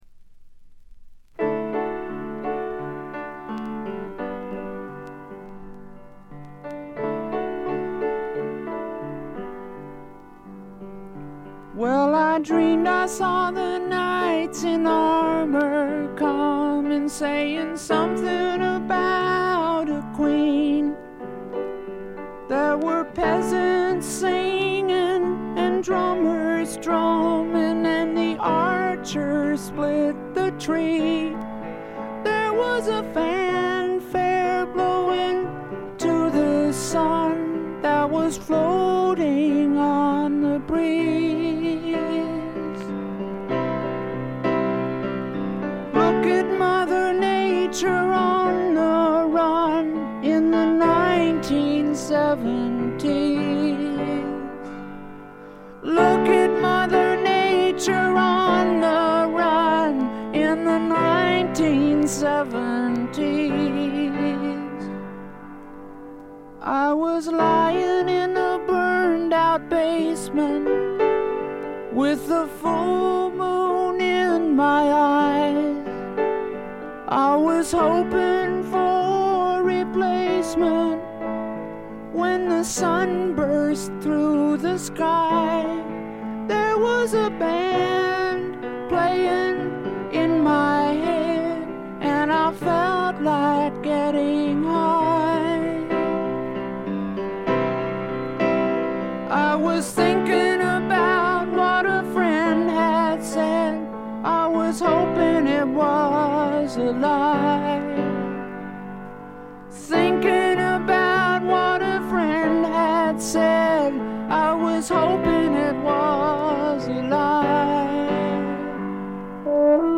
試聴曲は現品からの取り込み音源です。
piano, vocal
flugelhorn